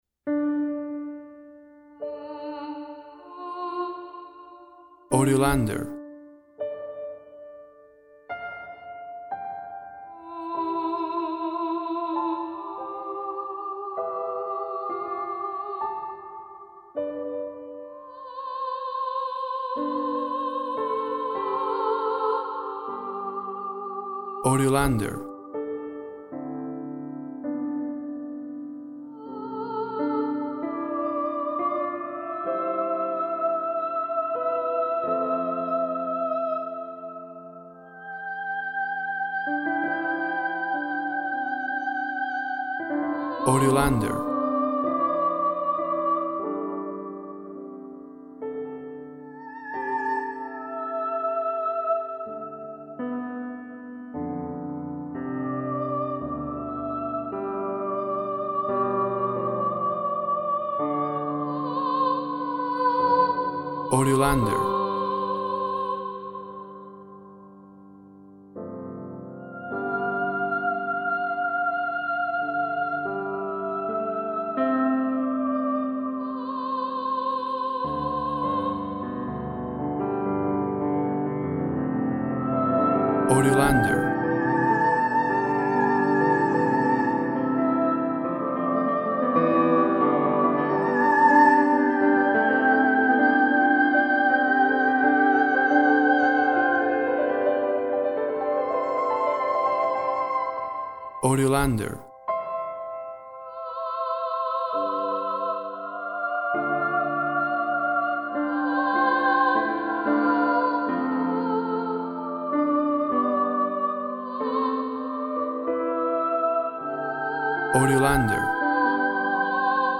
Melancholic atmosphere.